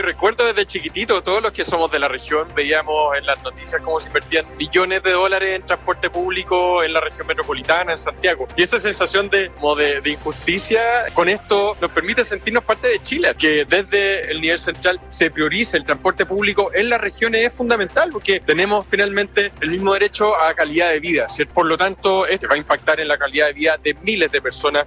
Por su parte, el alcalde de Puerto Varas, Tomás Gárate, indicó que por años se vio mayor inversión en transporte en la región Metropolitana, por lo que el -ahora- funcional tren, da un sentido de justicia.
tomas-garate-alcalde-puerto-varas-cuna.mp3